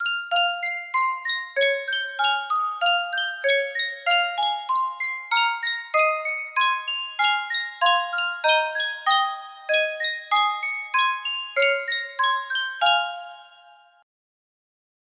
Sankyo Music Box - God Rest Ye Merry Gentlemen R6 music box melody
Full range 60